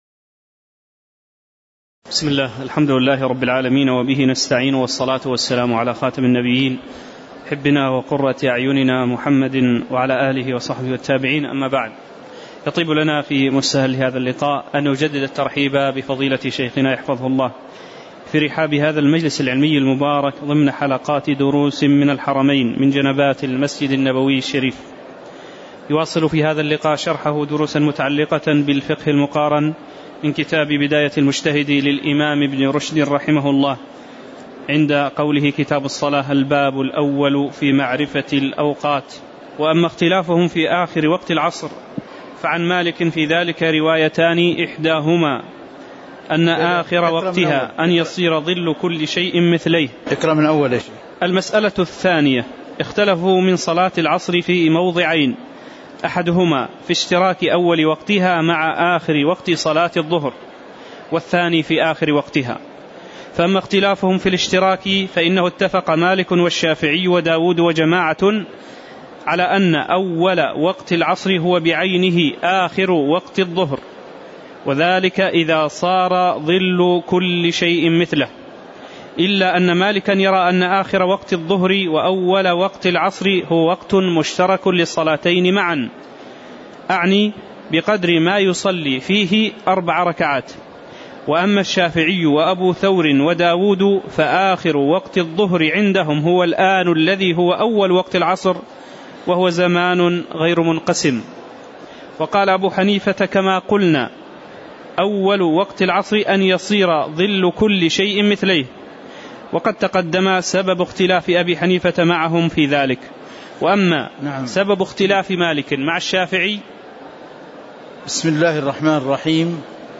تاريخ النشر ١ صفر ١٤٤١ هـ المكان: المسجد النبوي الشيخ